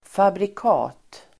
Ladda ner uttalet
fabrikat substantiv, manufacture , make Uttal: [fabrik'a:t] Böjningar: fabrikatet, fabrikat, fabrikaten Synonymer: märke Definition: tillverkning; märke Exempel: ett välkänt fabrikat (a well-known make)